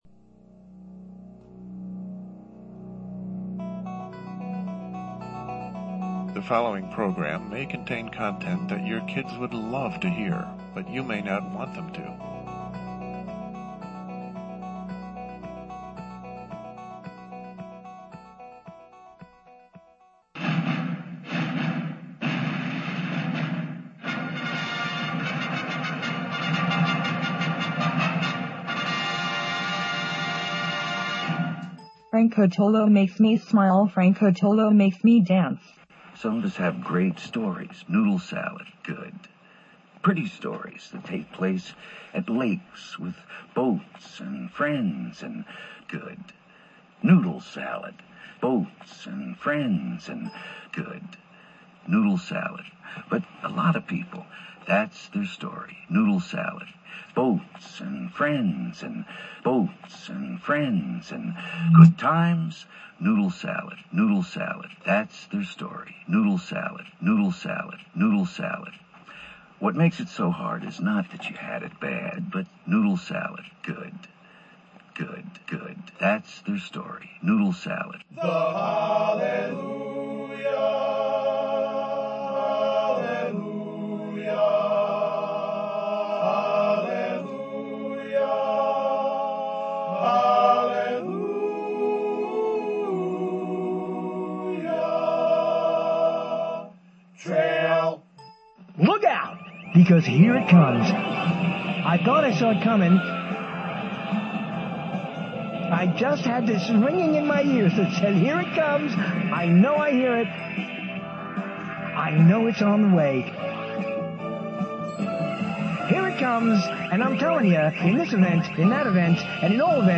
LIVE, Thursday, May 30 at 9 p.m. — LIVE.